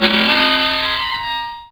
Avion05.wav